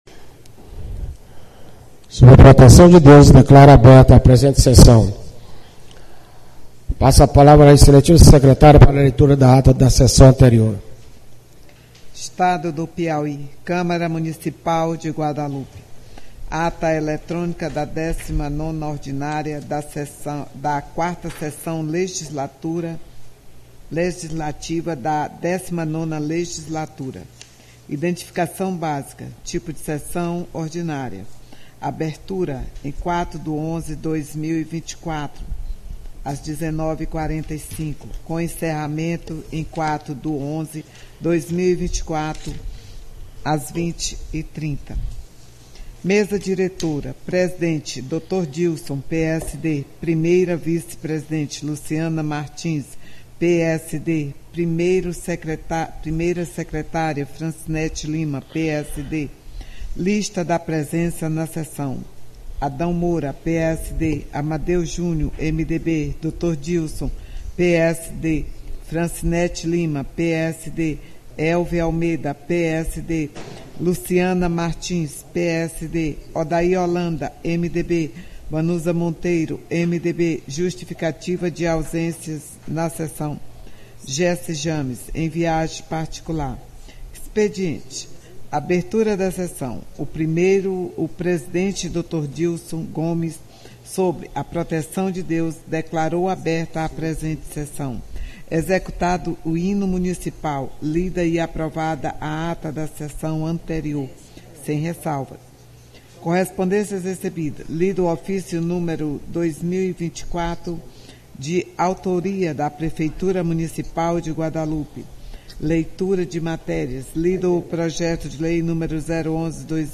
Mídias Sociais 3ª SESSÃO ORDINÁRIA PLENÁRIA 16 de março de 2026 áudio de sessões anteriores Rádio Câmara A Sessão da Câmara de Vereadores ocorre na segunda-feira, a partir das 19:30h.